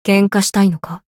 灵魂潮汐-南宫凛-互动-不耐烦的反馈2.ogg